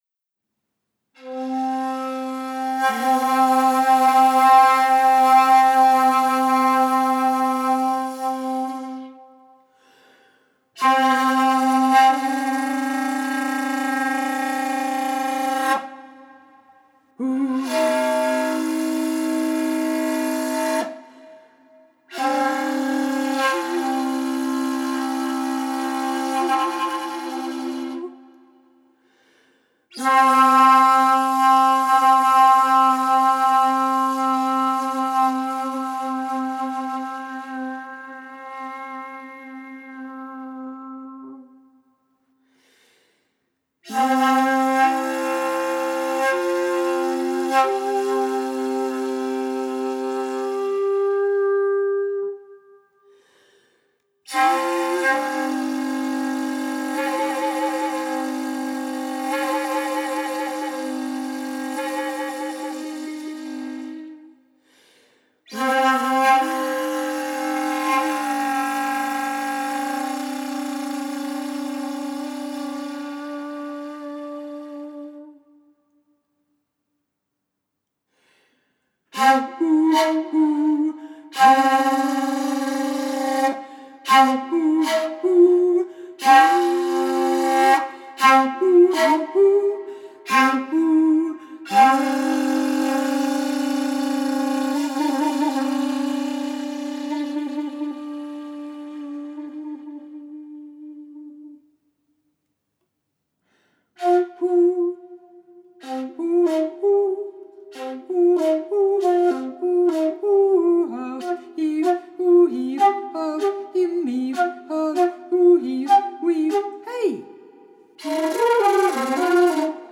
an existential duo for one
for solo shakuhachi
at The Chapel, Hobart, Tasmania